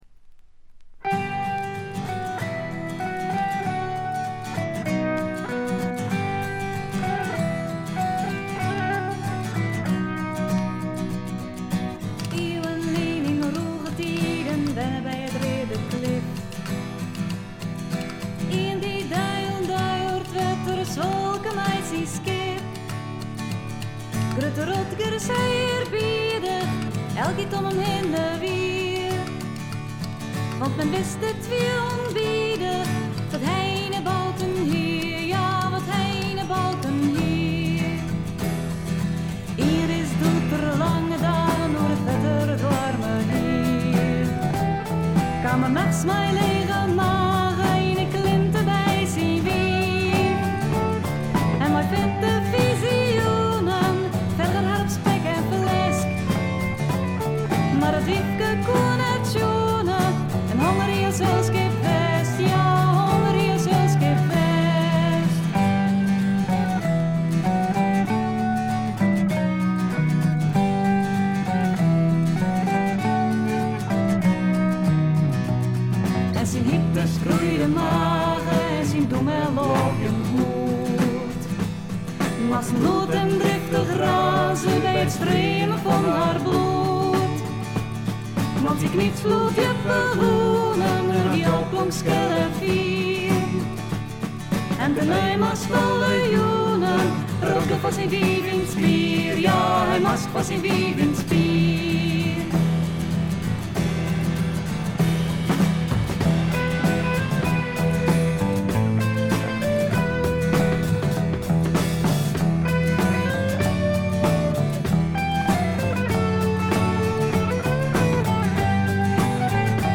部分試聴ですが、ほとんどノイズ感無し。
アコースティック楽器主体ながら多くの曲でドラムスも入り素晴らしいプログレッシヴ・フォークを展開しています。
試聴曲は現品からの取り込み音源です。